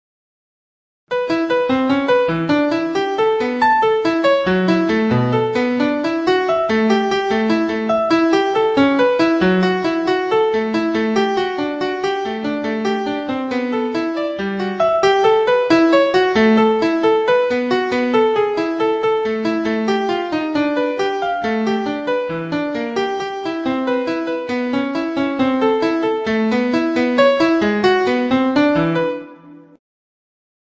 (The timing and dynamics are composed by the program and played by a MIDI player.)